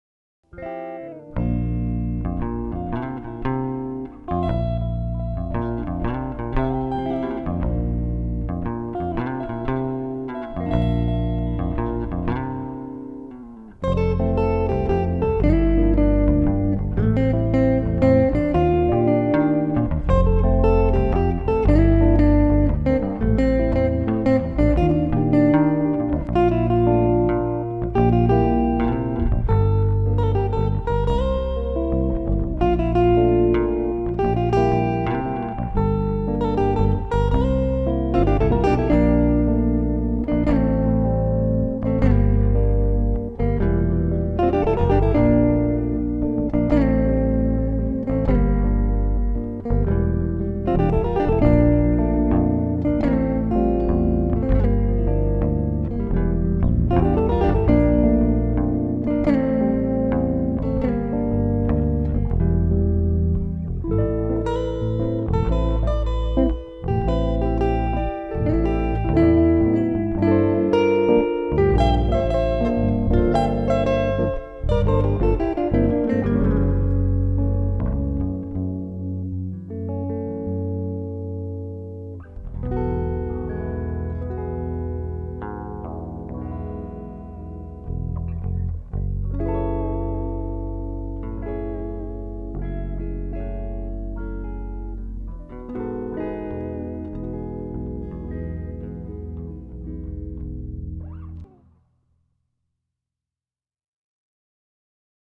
em teclado Yamaha PSR-E403